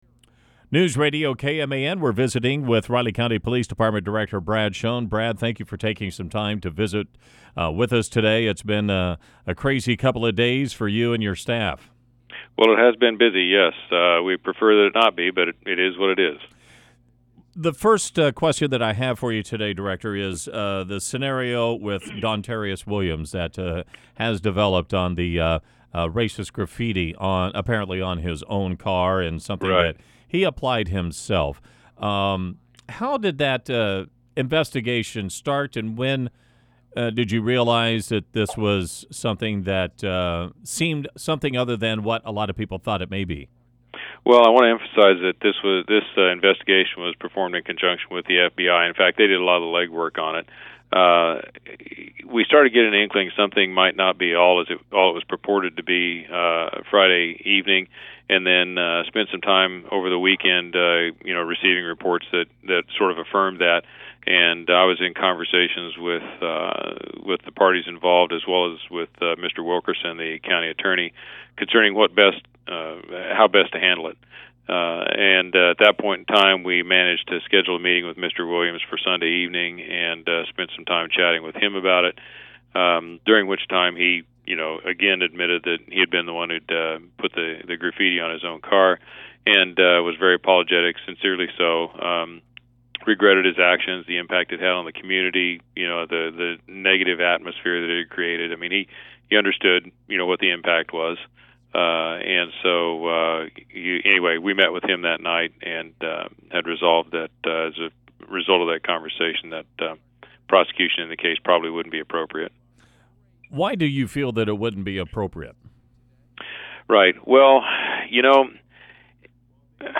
That full interview, which also included conversation on Monday afternoon’s shooting near University Crossing in Manhattan, can be heard below: